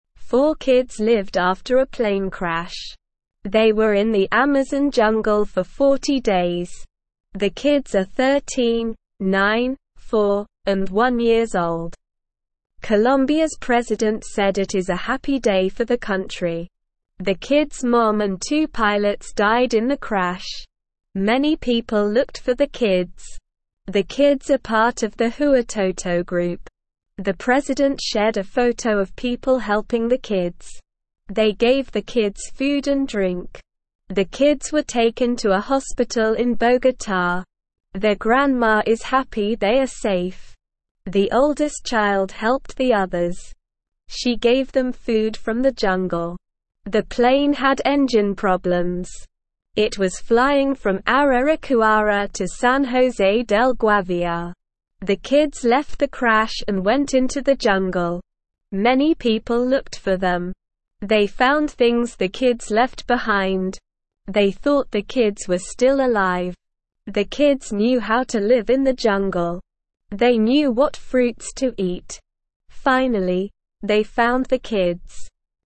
Slow
English-Newsroom-Beginner-SLOW-Reading-Four-Kids-Survive-Jungle-Plane-Crash.mp3